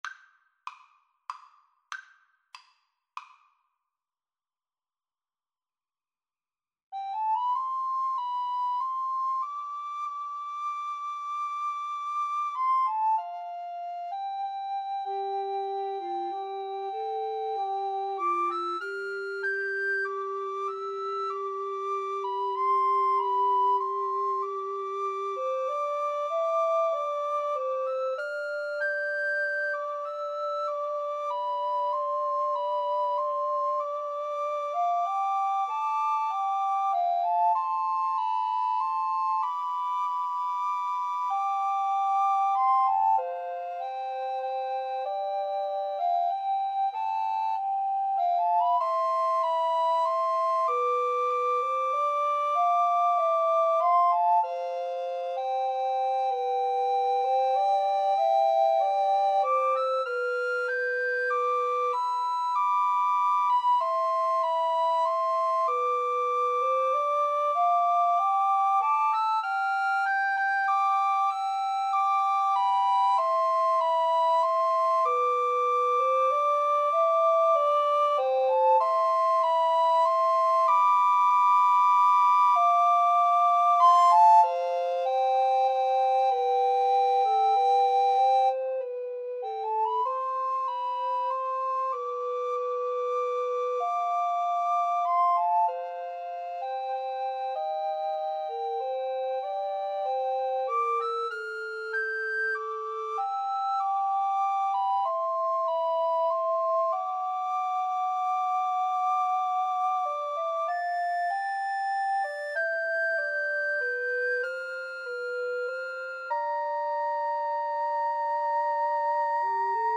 Soprano RecorderAlto RecorderTenor Recorder
3/4 (View more 3/4 Music)
C major (Sounding Pitch) (View more C major Music for Recorder Trio )
Andante = c. 96